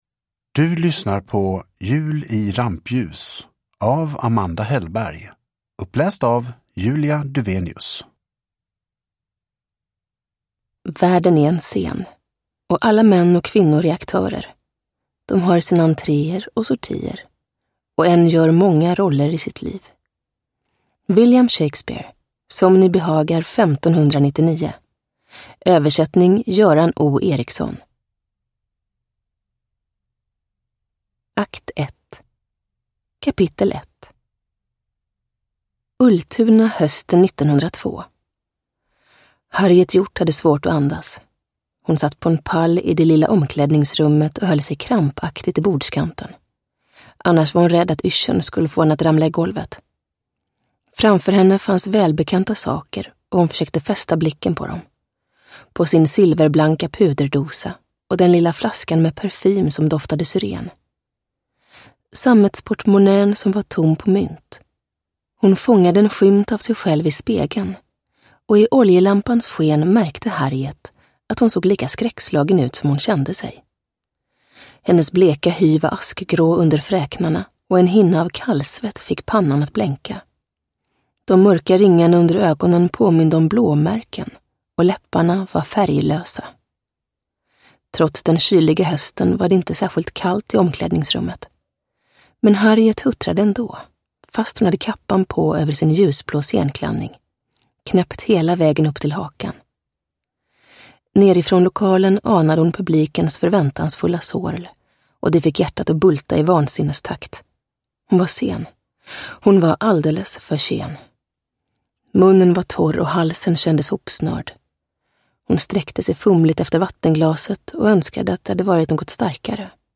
Uppläsare: Julia Dufvenius
Ljudbok